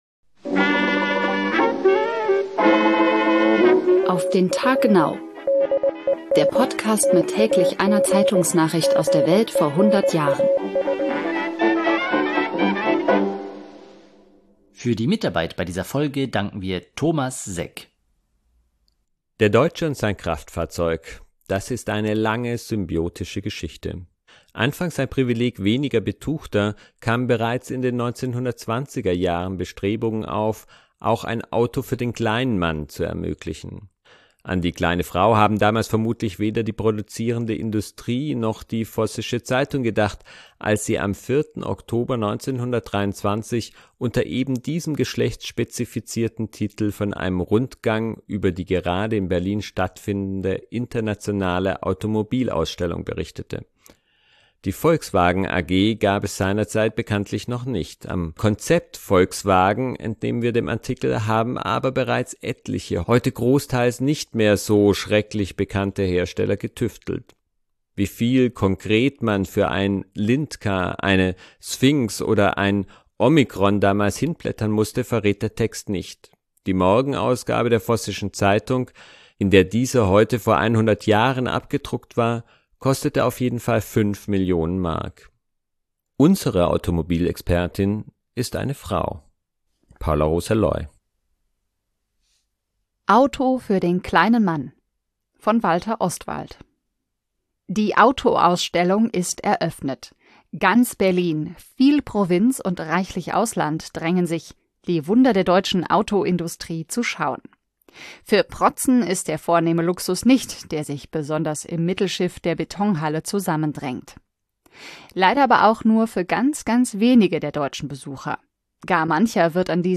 Unsere Automobilexpertin ist eine Frau: